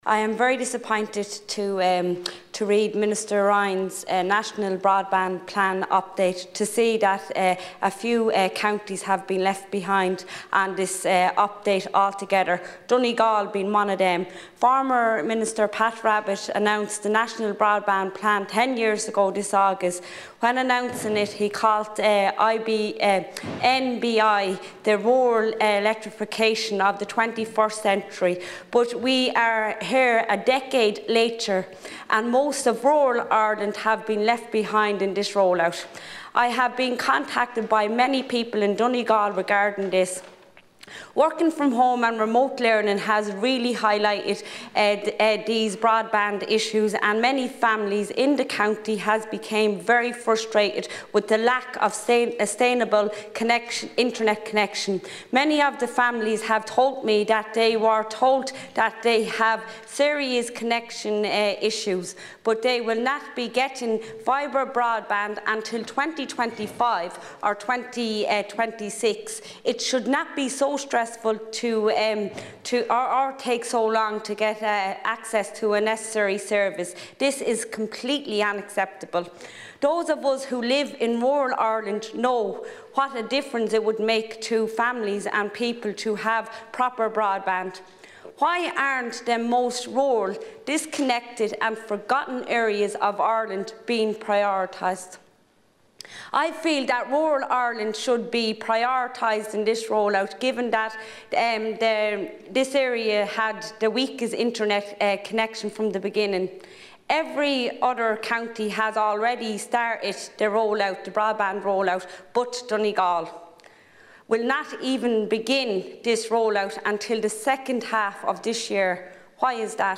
The Seanad has been told that giving people the right to ask if they can work from home is meaningless in many rural areas where the rollout of broadband is still a long way off.
Senator Flynn’s full contribution